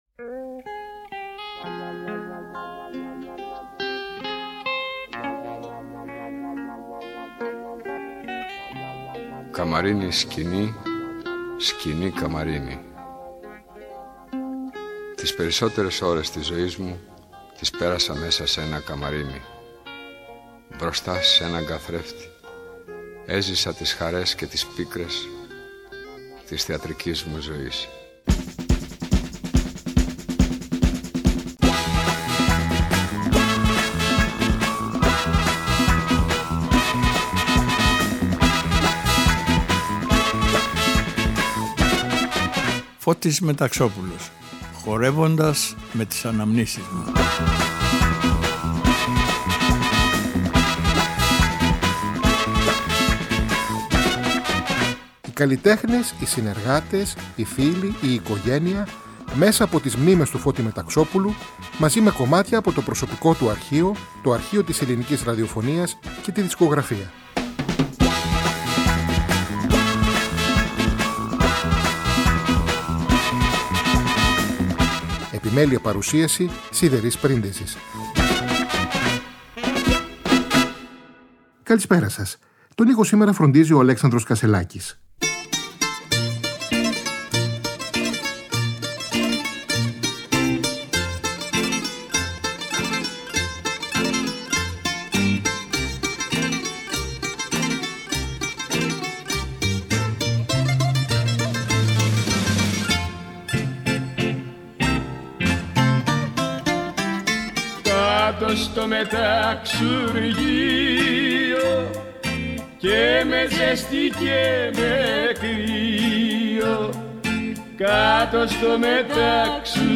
ΔΕΥΤΕΡΟ ΠΡΟΓΡΑΜΜΑ Αφιερώματα Μουσική Συνεντεύξεις